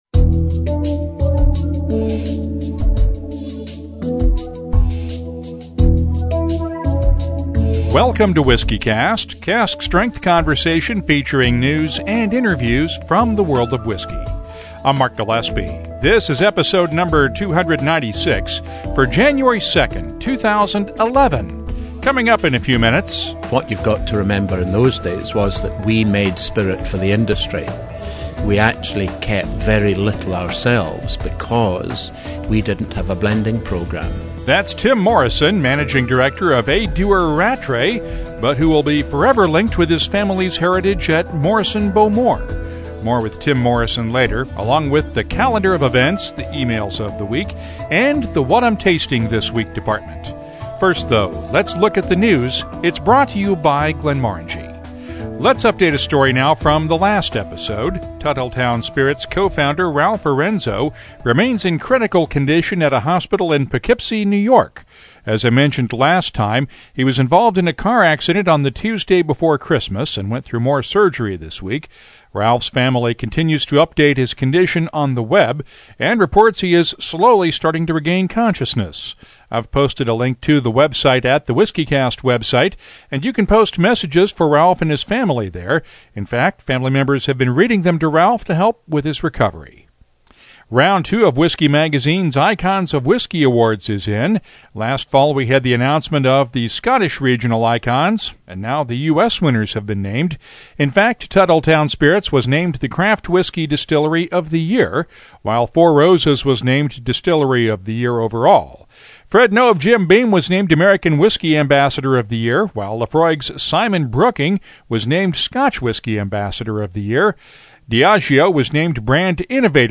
We’ll start off the New Year with a rare interview